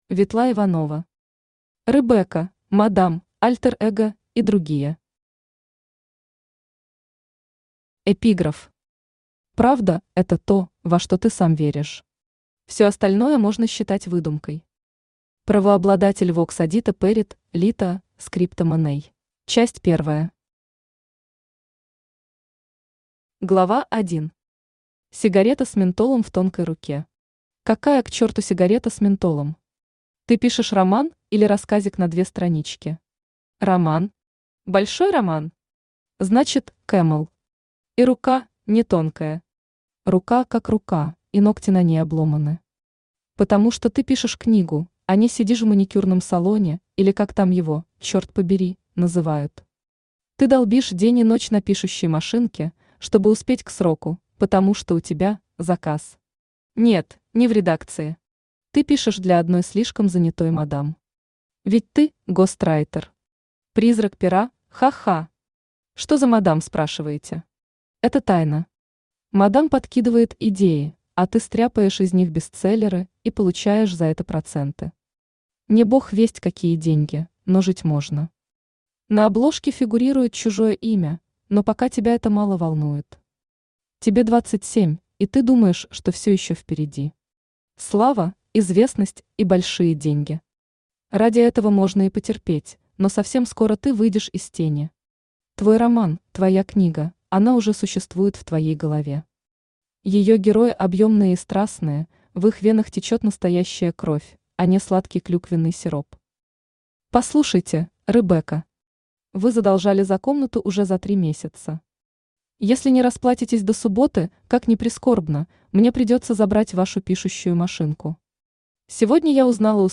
Aудиокнига Ребекка, Мадам, Альтер эго и другие Автор Ветла Иванова Читает аудиокнигу Авточтец ЛитРес.